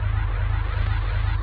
shield.mp3